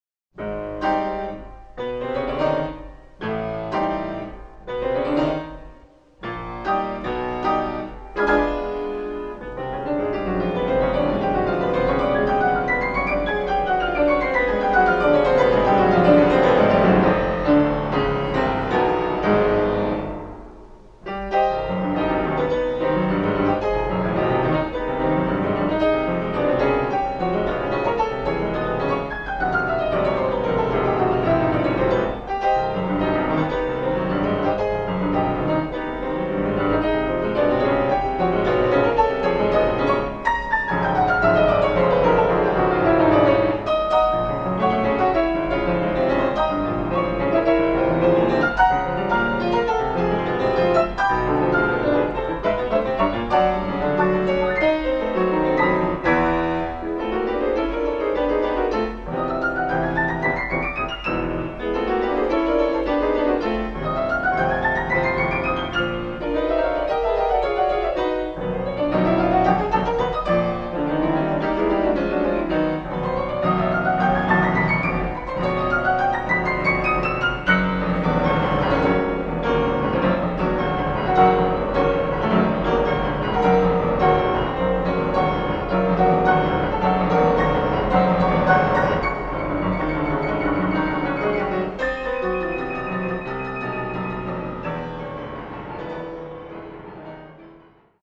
"Annèes de Pèlerinage" de piano Orages.